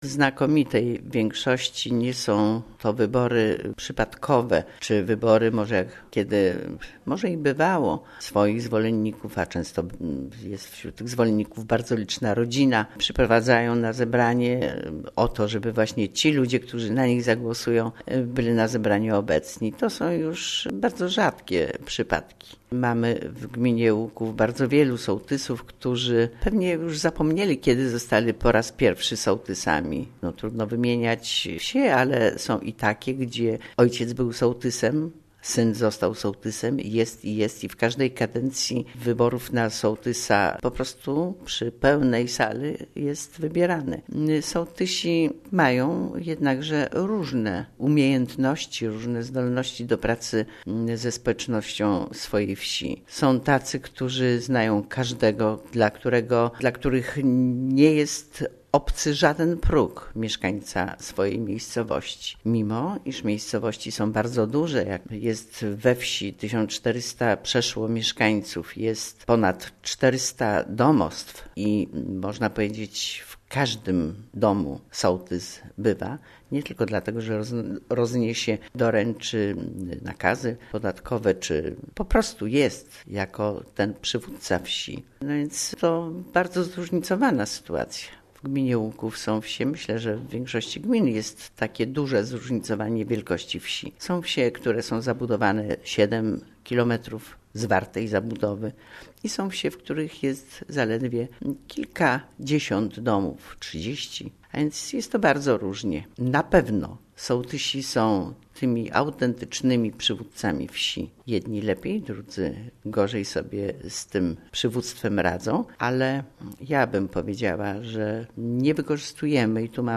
W�jt Gminy �uk�w Kazimiera Go�awska